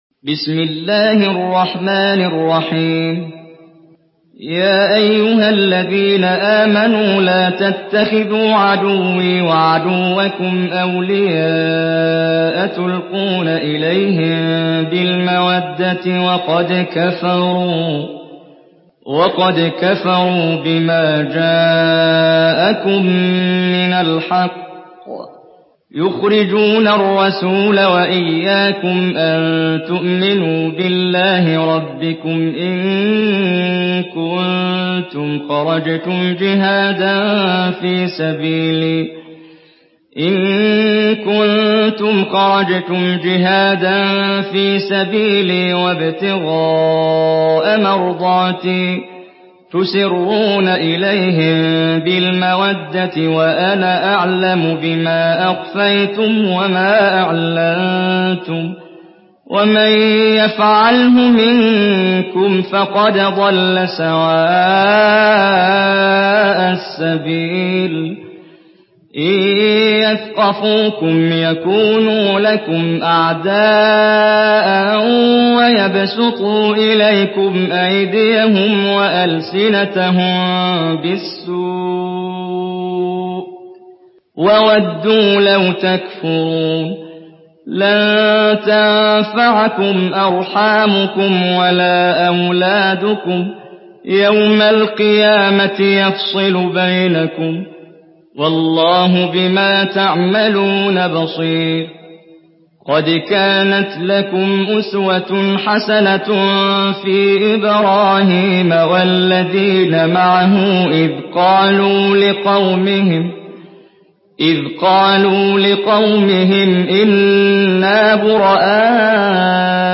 تحميل سورة الممتحنة بصوت محمد جبريل
مرتل حفص عن عاصم